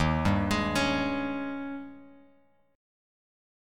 D#11 chord